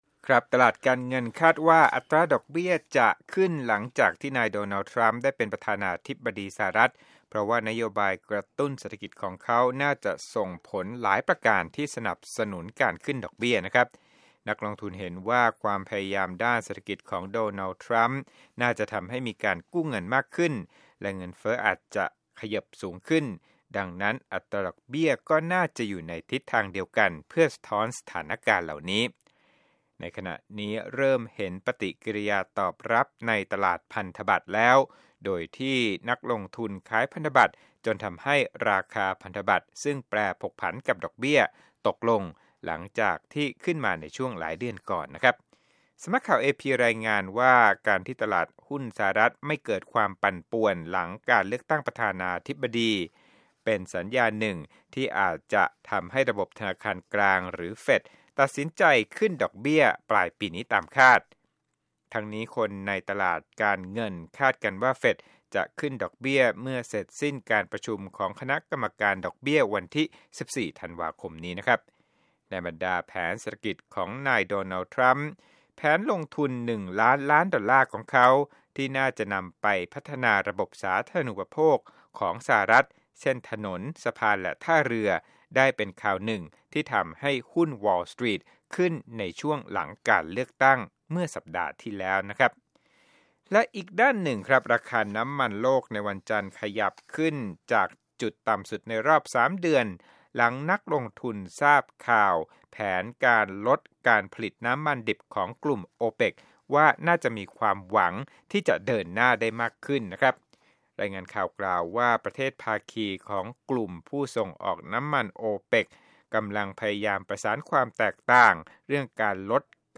Business News